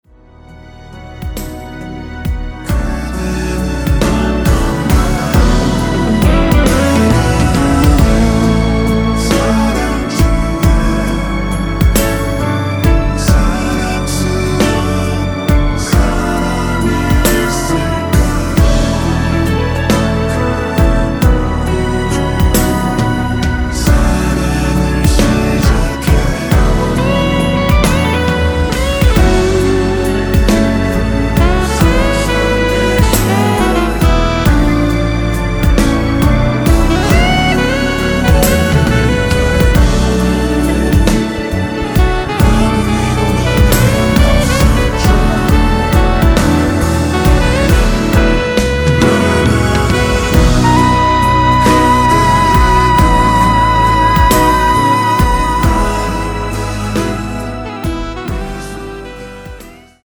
순수 코러스만 들어가 있으며 멤버들끼리 주고 받는 부분은 코러스가 아니라서 없습니다.(미리듣기 확인)
원키에서(-2)내린 코러스 포함된 MR입니다.
앞부분30초, 뒷부분30초씩 편집해서 올려 드리고 있습니다.
중간에 음이 끈어지고 다시 나오는 이유는